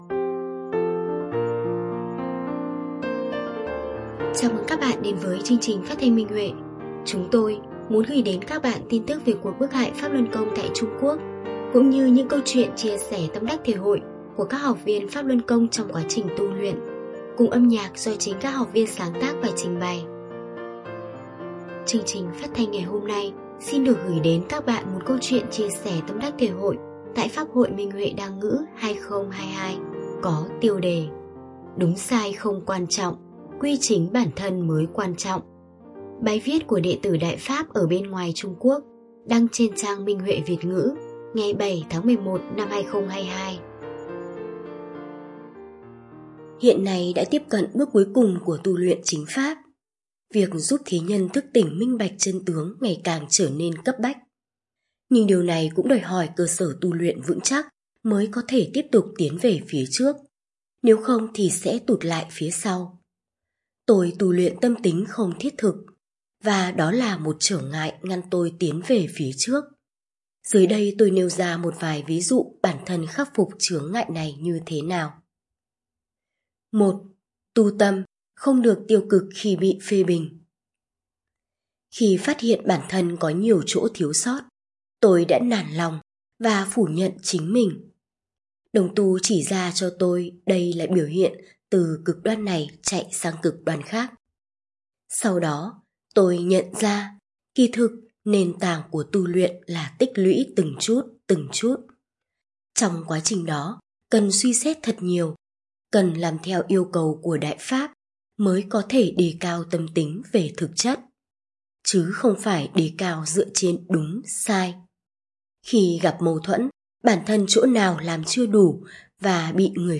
Phát thanh Minh Huệ (Câu chuyện tu luyện): Đúng sai không quan trọng, quy chính bản thân mới quan trọng